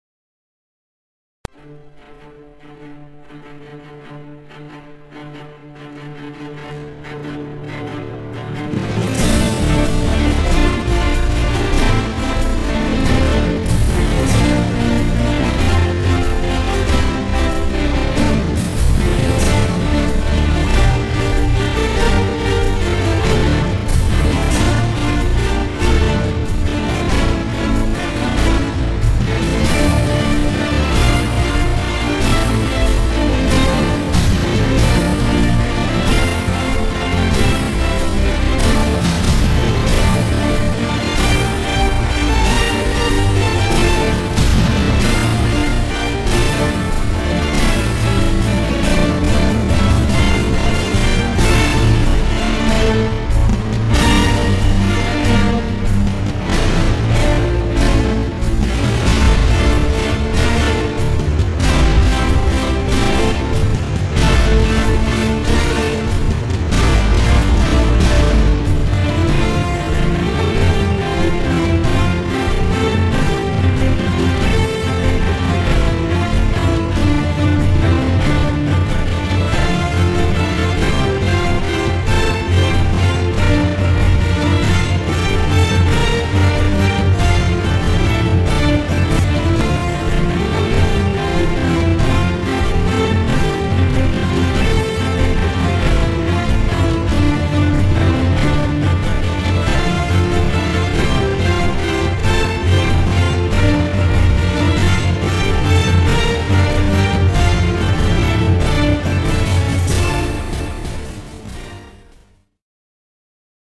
Instrumentacions